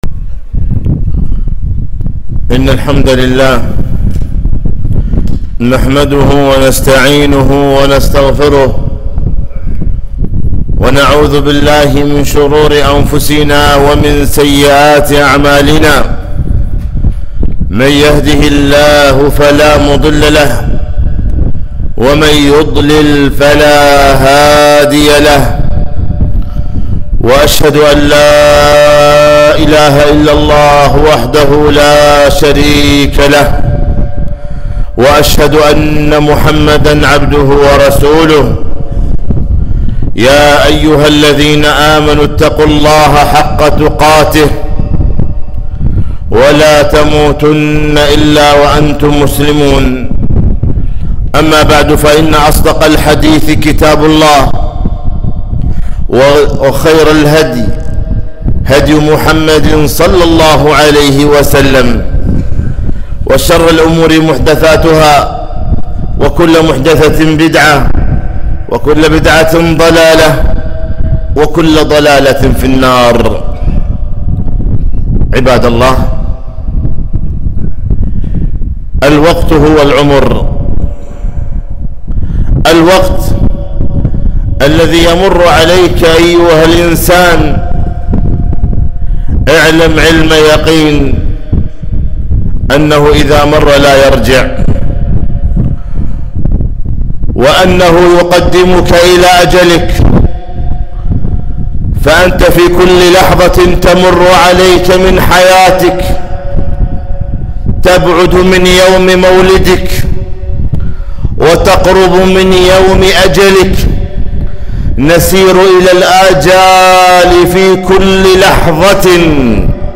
خطبة - ( اغتنام الأوقات في الإجازات)